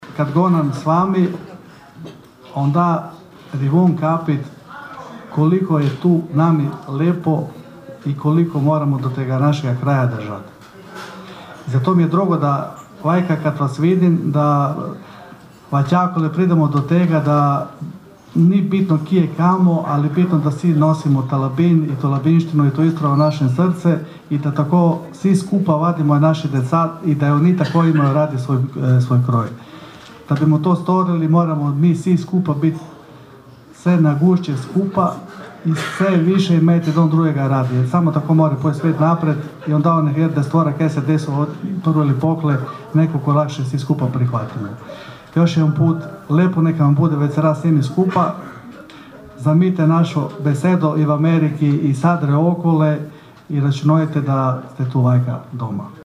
Stotinjak iseljenika koji trenutačno borave u domovini, uglavnom iz SAD-a, ali i Kanade te europskih zemalja Francuske i Njemačke, okupilo se sinoć u Konobi 'Bukaleta' na Dubrovi, na Godišnjem susretu iseljenika Labinštine.